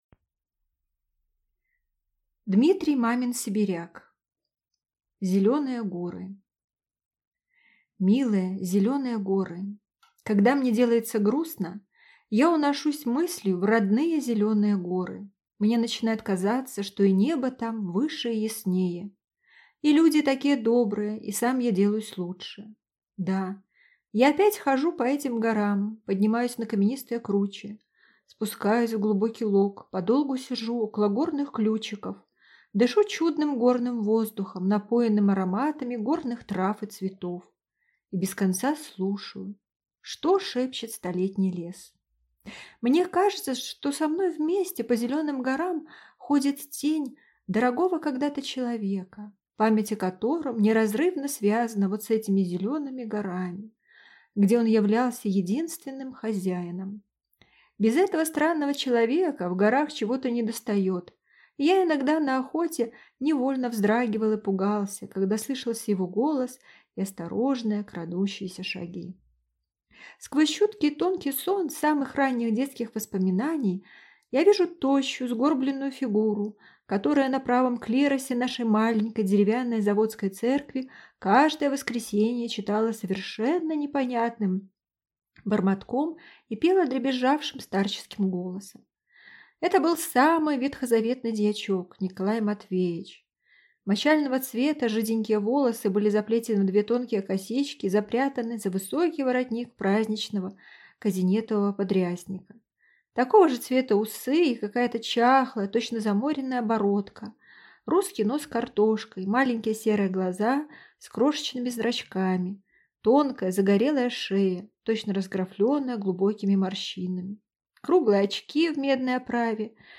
Аудиокнига Зеленые горы | Библиотека аудиокниг